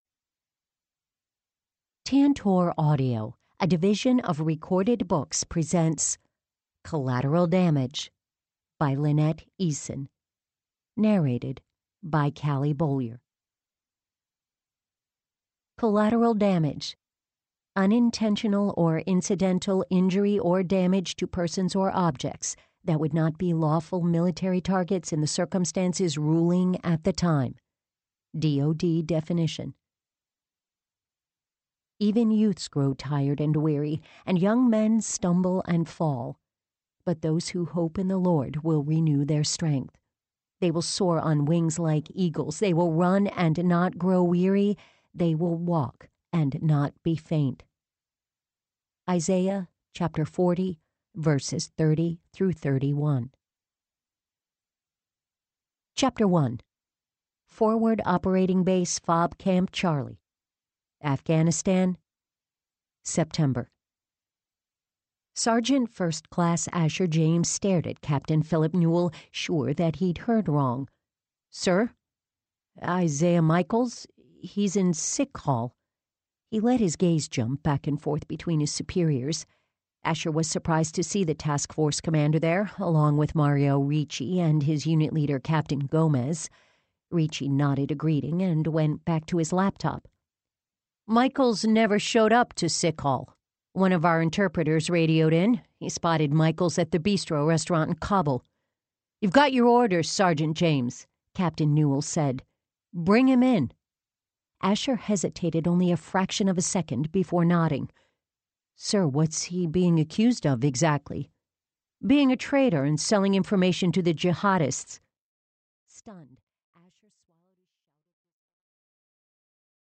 Format: Eaudiobook, Audio Books, Nonmusical Sound Recording, Sound Recording, Electronic Resources Author: Eason, Lynette, author. Title: Collateral damage / Lynette Eason.
Audiobooks.